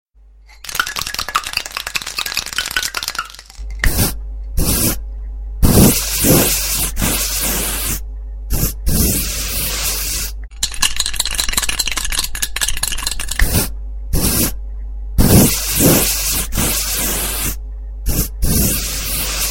3D Tones